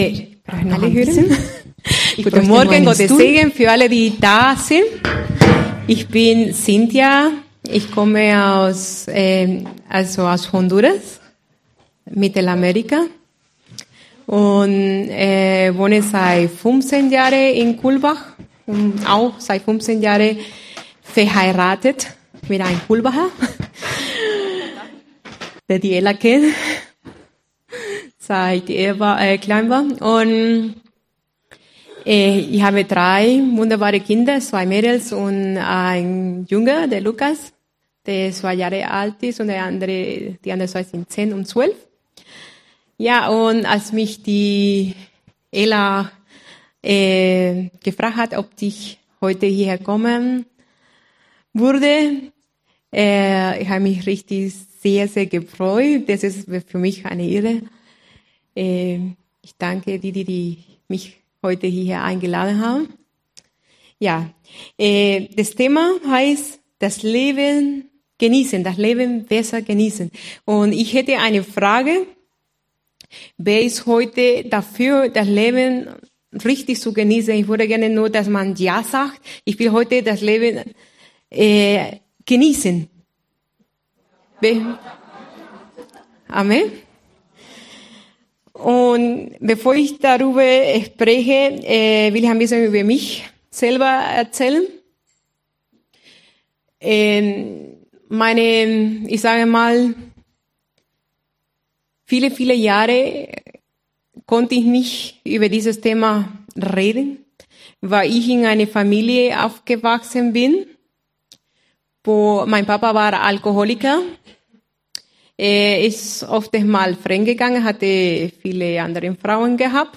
Wie hat dir diese Predigt gefallen?